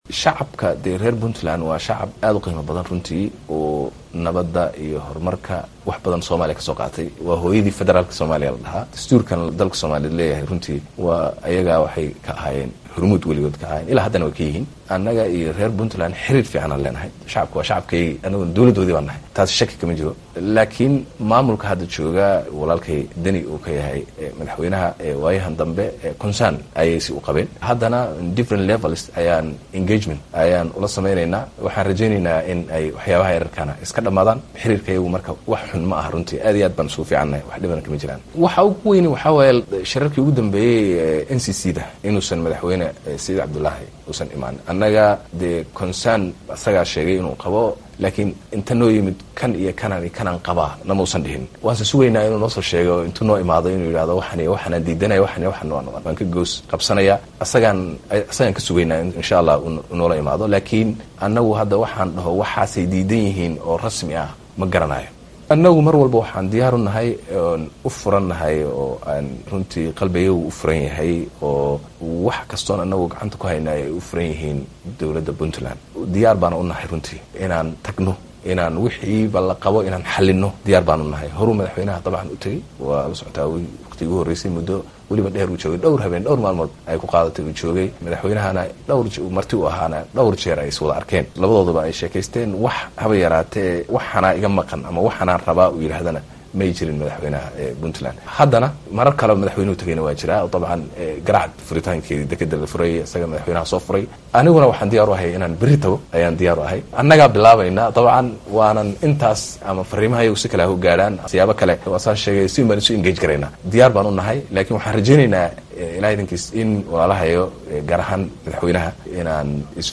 Ra’iisul wasaaraha Soomaaliya oo u warramay laanta afka soomaaliga ee VOA-da ayaa sheegay in Saciid Deni uu ka sugaya inuu ula yimaado waxaa uu xukuumaddiisa ka tirsanayo.